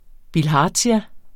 Udtale [ bilˈhɑːdɕa ]